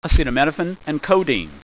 Pronunciation
(a seet a MIN oh fen & KOE deen)